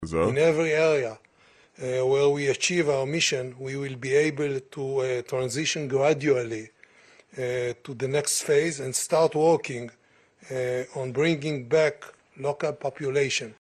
ディクテーション VOA News 23年12月19日午後７時（現地時間）のニュース イスラエルは次のフェーズに移行し、ガザ地区に現地民を戻すと発言したという話題です。